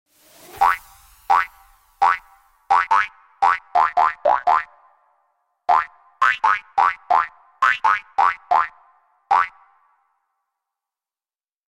Hoppelnd durch den Retro-Sound